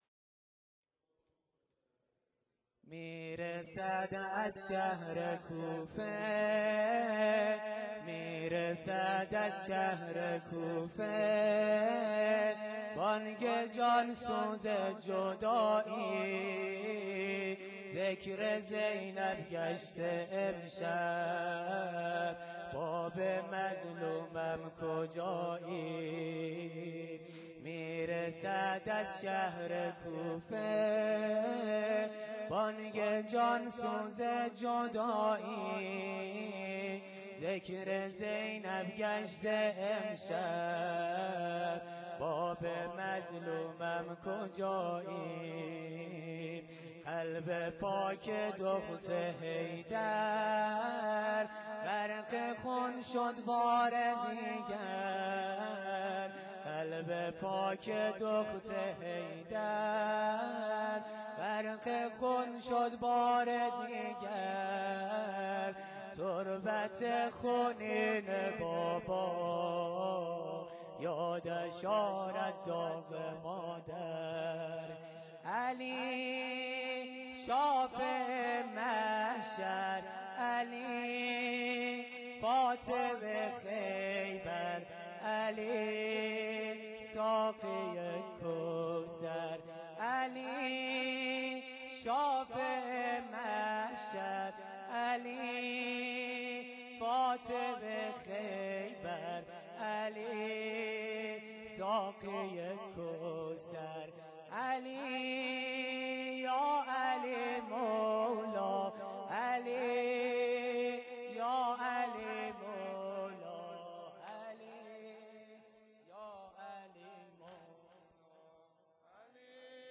واحد _ میرسد از شهر کوفه